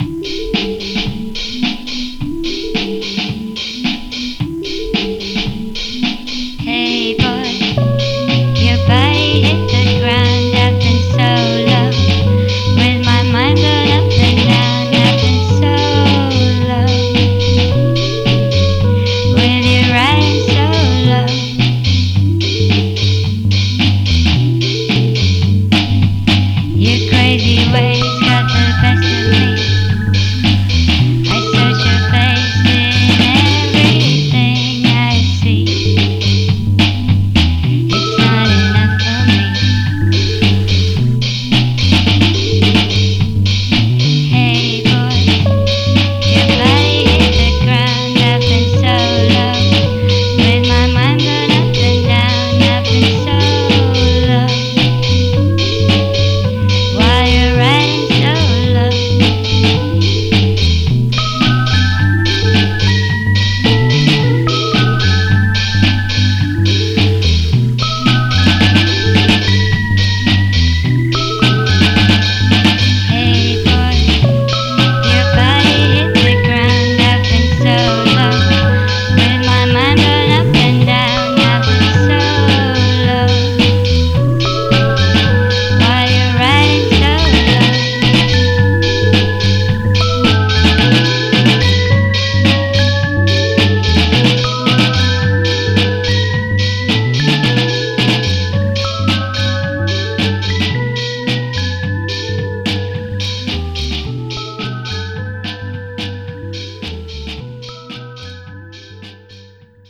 Un disco notturno.